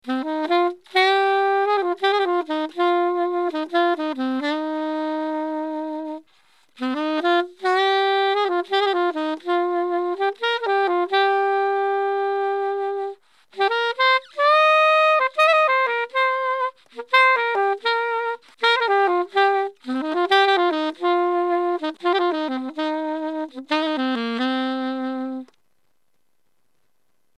テナーのような分厚いサウンドとパワーのある音調が特徴です。
サンプル音源1　IWサテンアルト
funk-matteas-linkfl.mp3